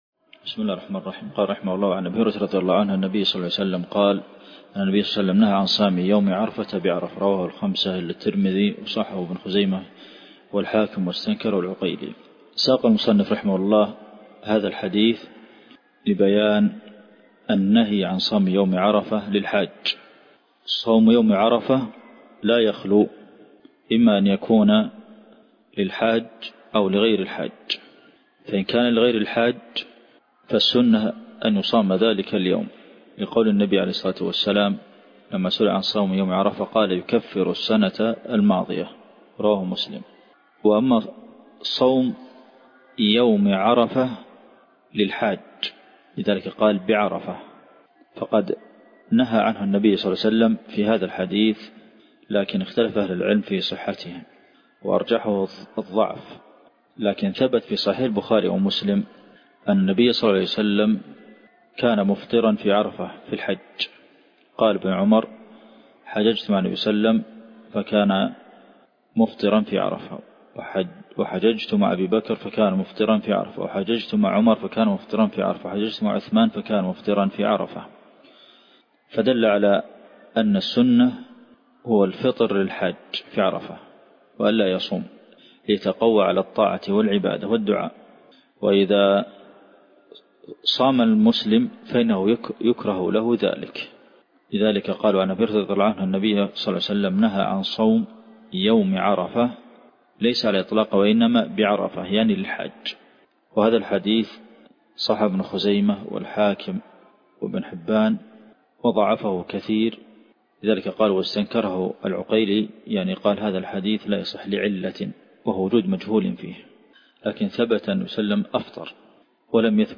الدرس (15) شرح بلوغ المرام دورة في فقه الصيام - الشيخ عبد المحسن القاسم